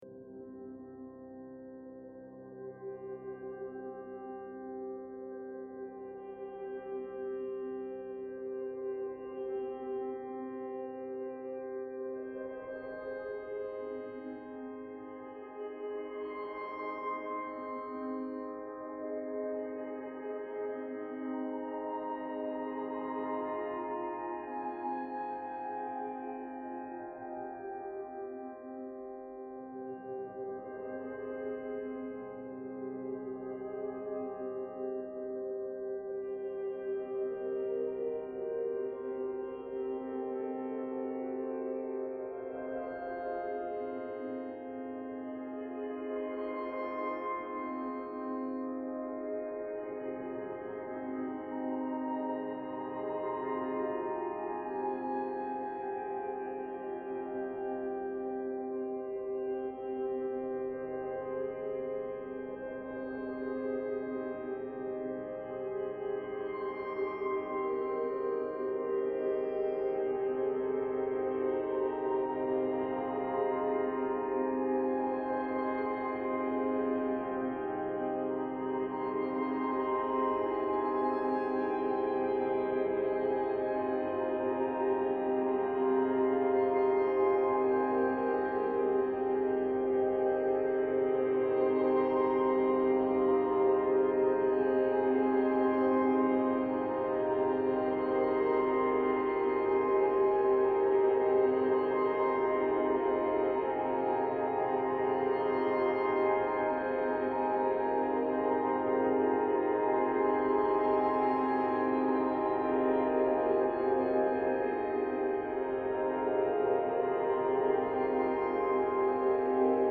20 superimposed versions of the same recording